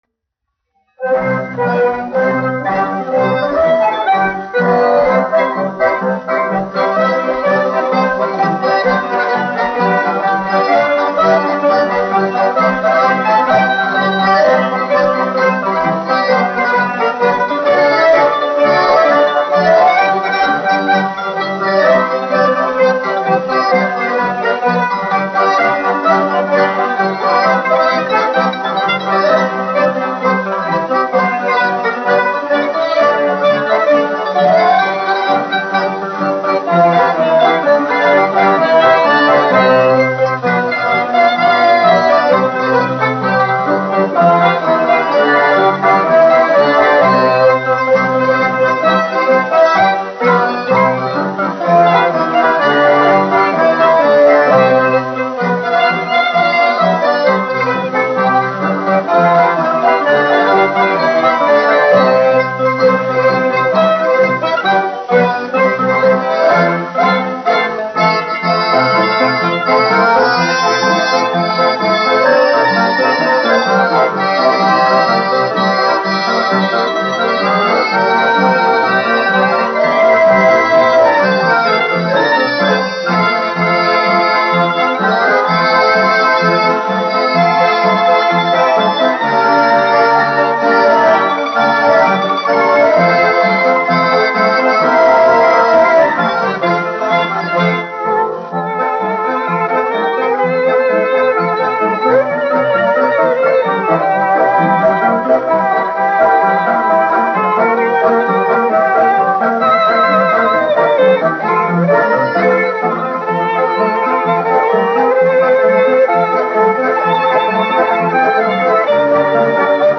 1 skpl. : analogs, 78 apgr/min, mono ; 25 cm
Populārā instrumentālā mūzika
Marši
Skaņuplate
Latvijas vēsturiskie šellaka skaņuplašu ieraksti (Kolekcija)